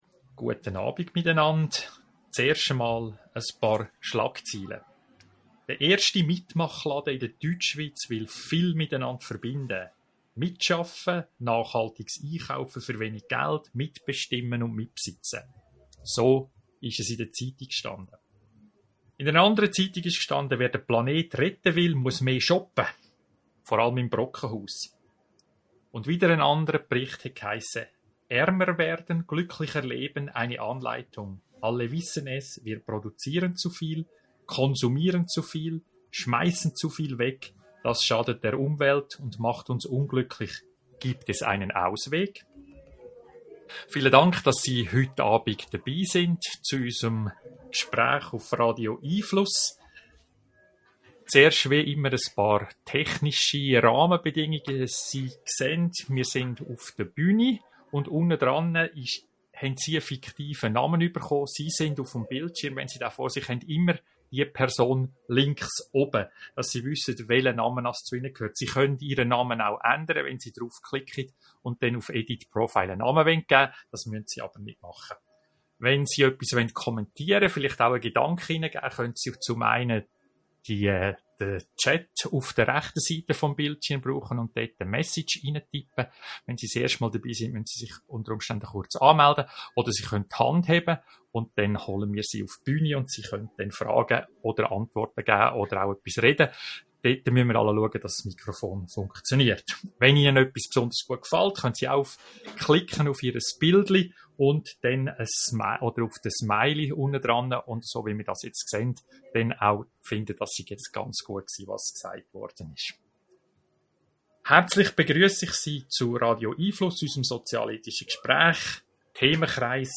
Radio 🎙 einFluss findet jeden Mittwochabend von 18.30 - 19 Uhr statt. Live mitdiskutieren oder anonym zuhören - wir freuen uns auf Sie!